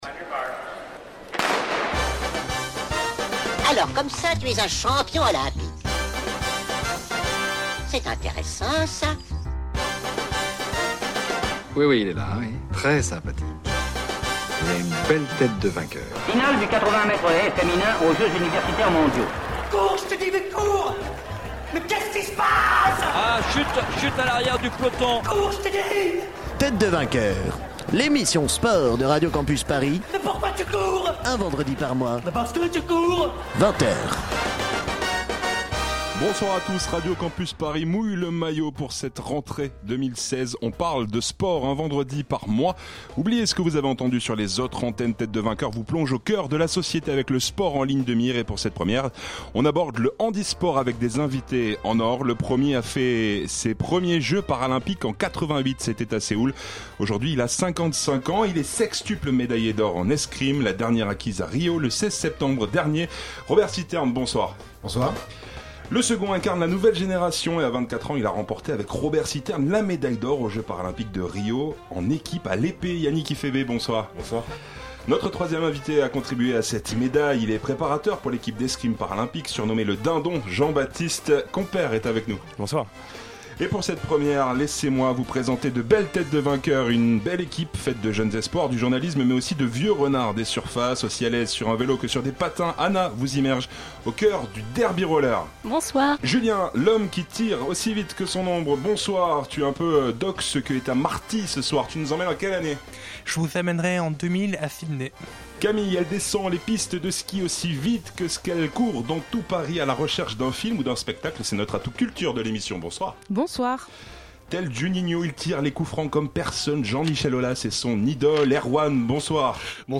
Pour cette première de « Têtes de vainqueurs », le handisport est à l'honneur. L'équipe reçoit deux escrimeurs, deux médaillés d'or paralympique aux derniers jeux de Rio à l'épée en équipe.